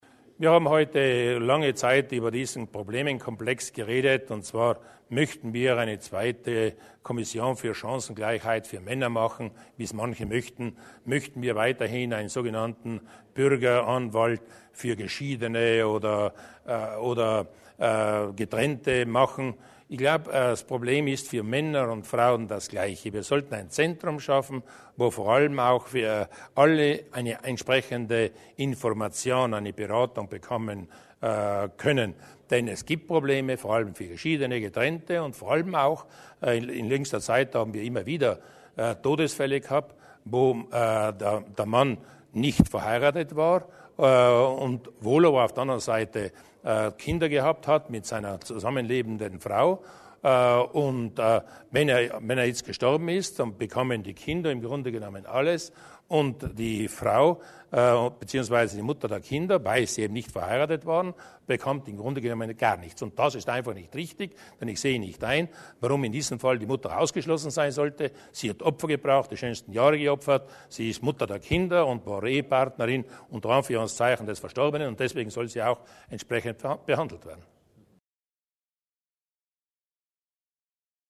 Landeshauptmann Durnwalder zu den Maßnahmen für Getrennte und Geschiedene